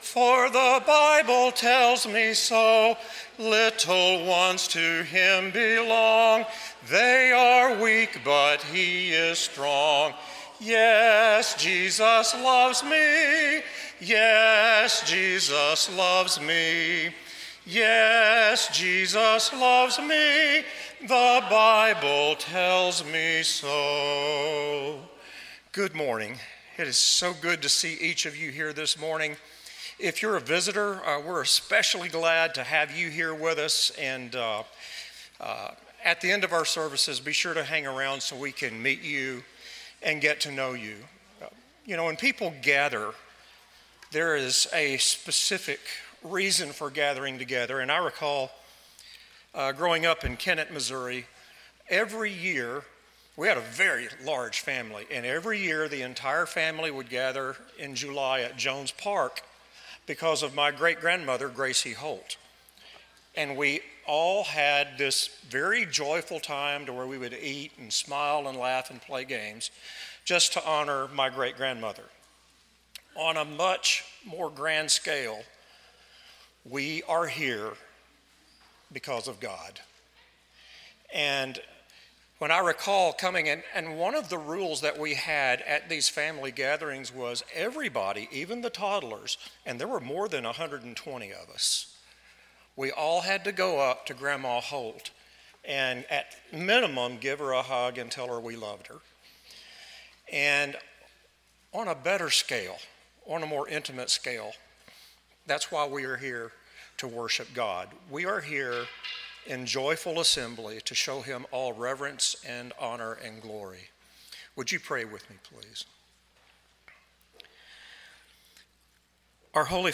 John 8:32, English Standard Version Series: Sunday AM Service